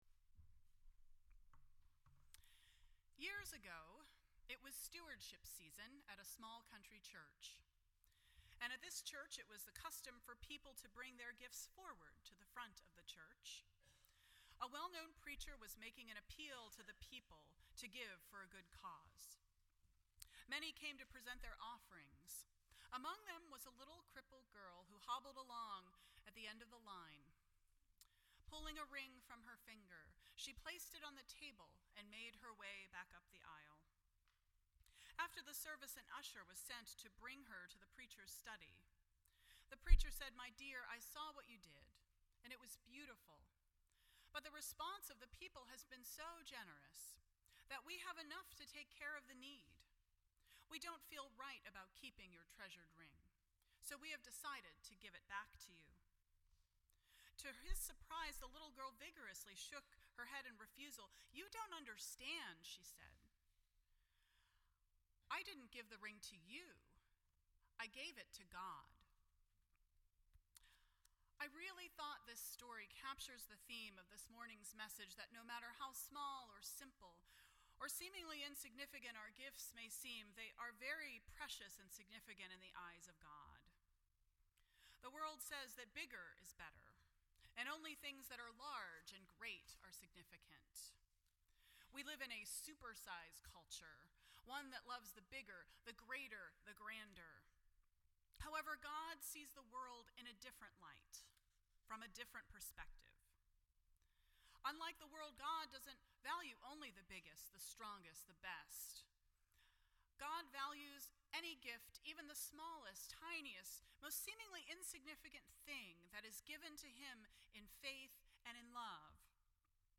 None Service Type: Sunday Morning %todo_render% Share This Story